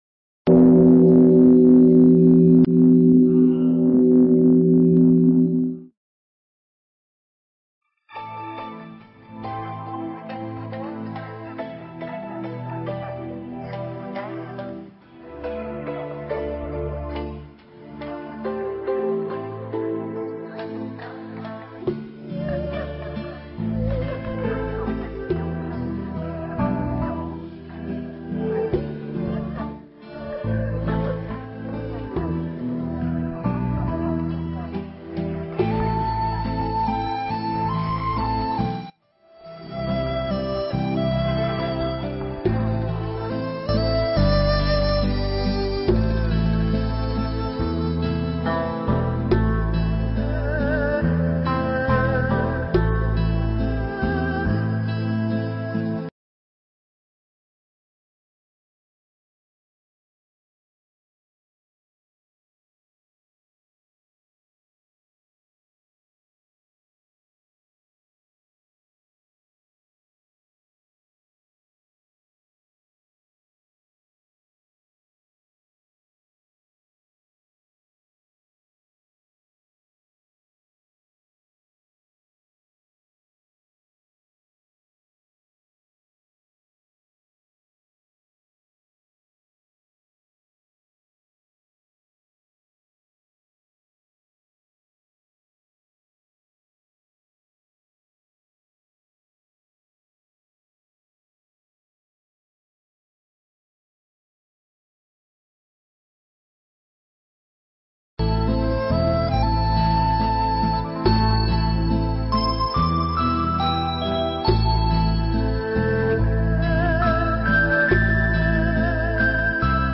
Nghe Mp3 thuyết pháp Quả Báo Sát Sanh